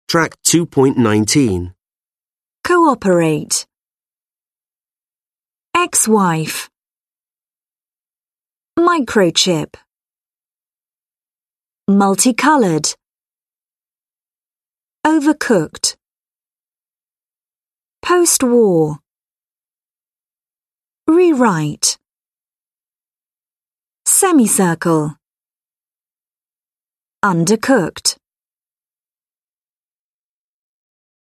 4 (trang 63 Tiếng Anh 10 Friends Global) Pronunciation. Listen to the examples in the table and mark the stress.
/koʊˈɑː.pə.reɪt/
/ˌeksˈwaɪf/
/ˈmaɪ.kroʊ.tʃɪp/
/ˌmʌl.tiˈkʌl.ɚd/
/ˌoʊ.vɚˈkʊkt/
/ˈpoʊst.wɔːr/
/ˌriːˈraɪt/
/ˈsem.iˌsɝː.kəl/
/ˌʌn.dɚˈkʊkt/